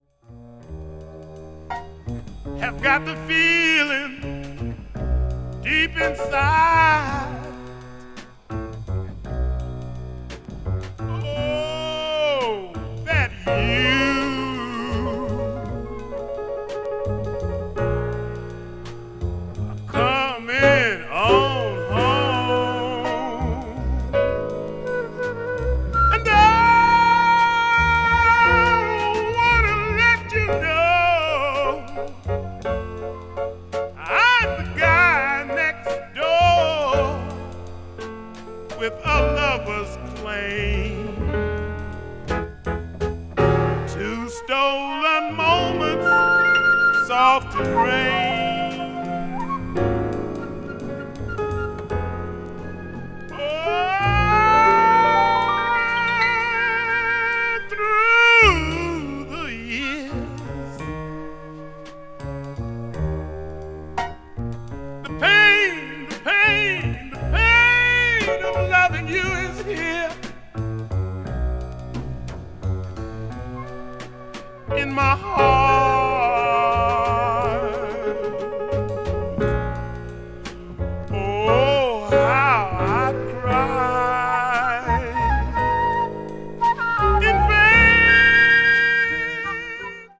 blues jazz   jazz vocal   post bop